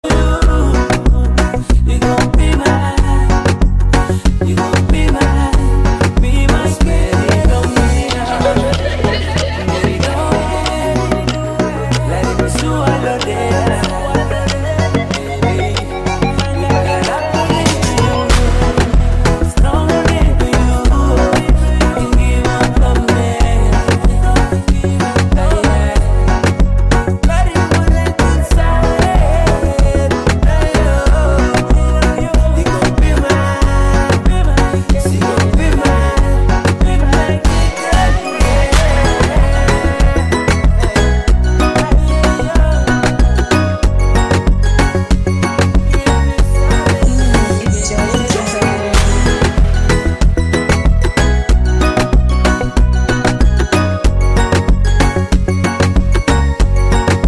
Local Swing Bounce